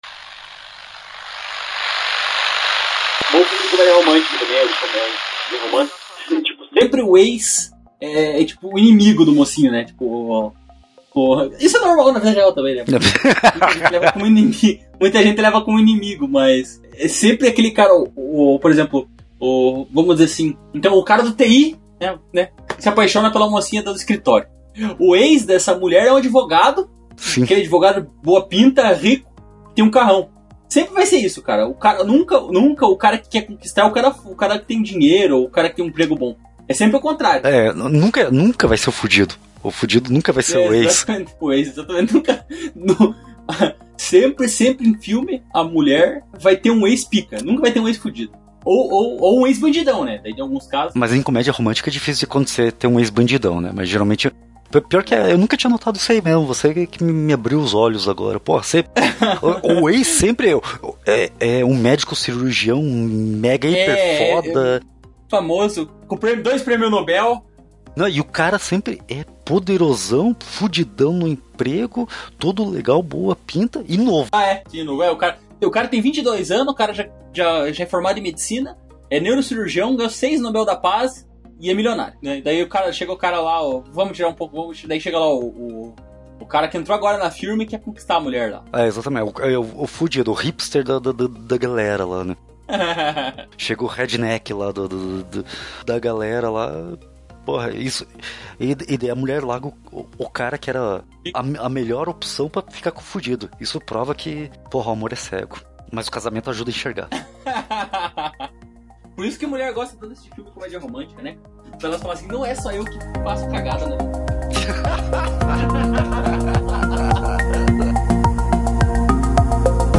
O podcast “VariaçõesCast” é um dos programas da família Variações de um Nerd, aqui batemos um papo sobre filmes, séries e outros conteúdos da cultura pop/nerd.